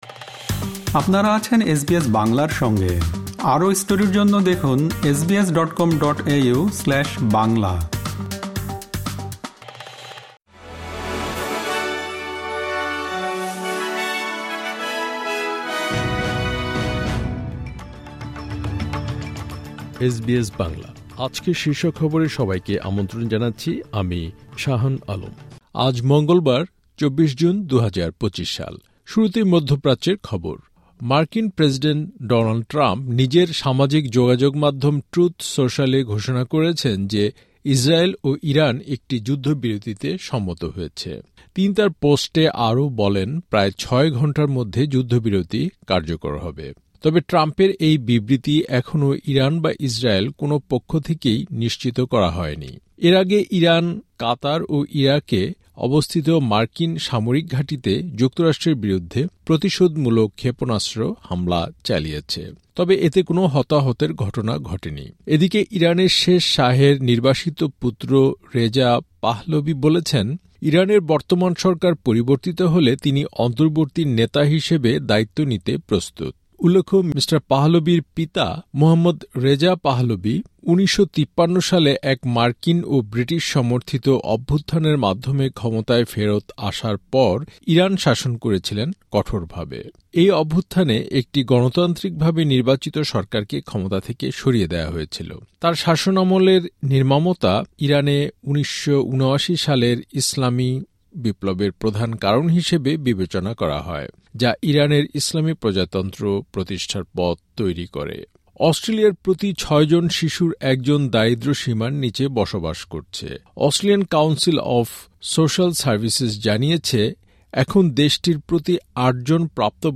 এসবিএস বাংলা শীর্ষ খবর: ২৪ জুন, ২০২৫